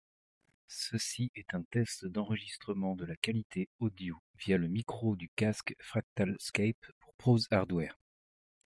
Đây là một mẫu thử trong môi trường yên tĩnh. Giọng nói rõ ràng dù có một vài tiếng rít nhỏ có thể nghe được.
• [Mẫu âm thanh Micro rời trong môi trường yên tĩnh]